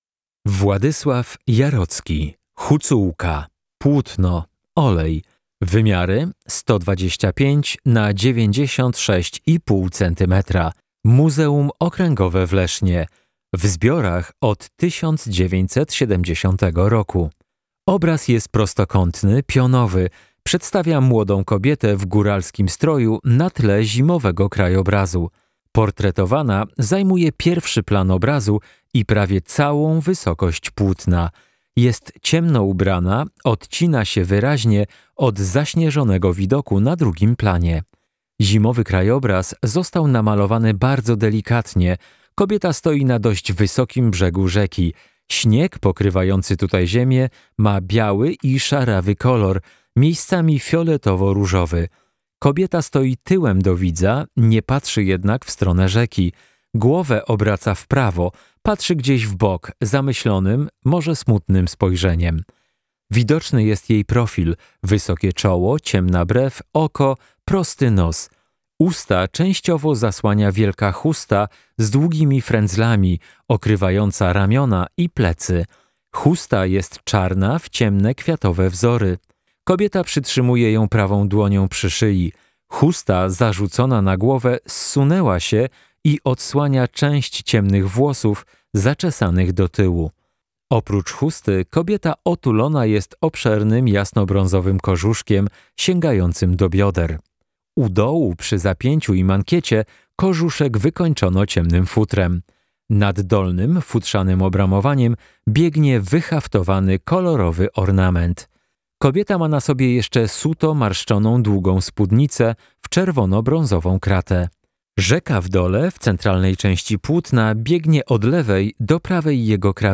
Audiodeskrypcja -